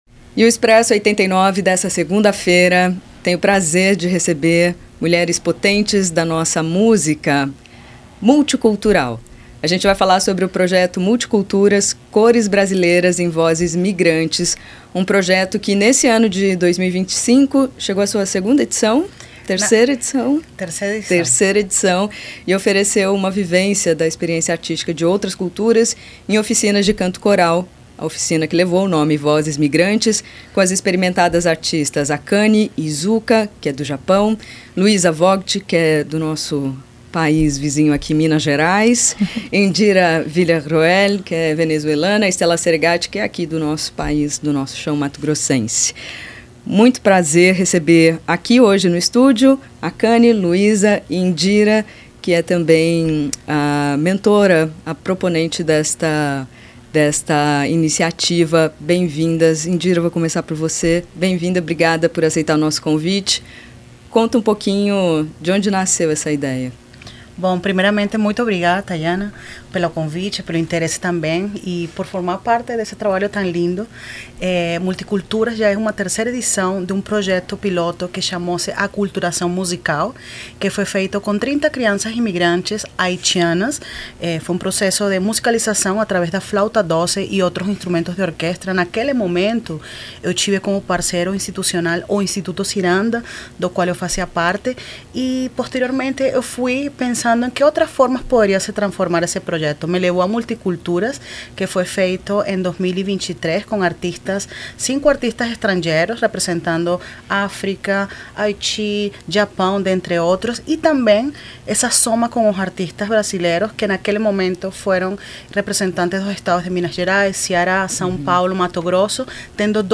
Expresso 89 Entrevista: Projeto Multiculturas